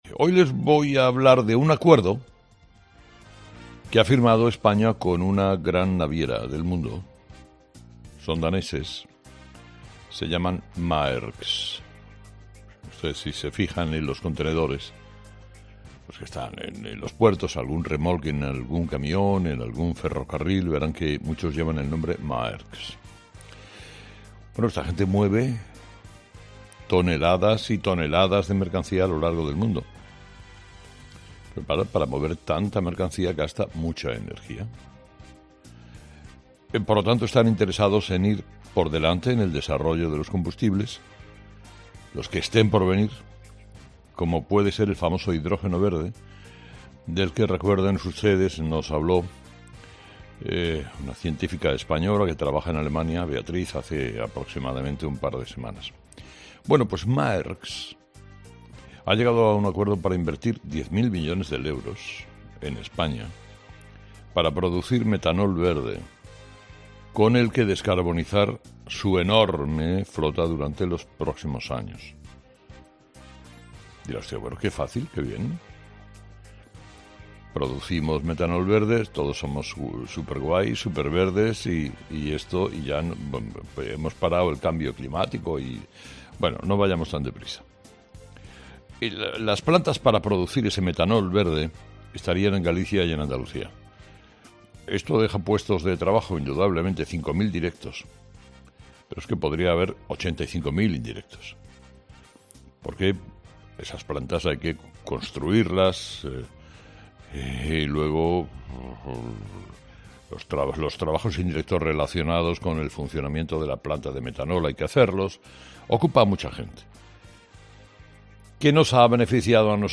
Carlos Herrera explica qué es el metanol verde y lo que significaría para nuestro país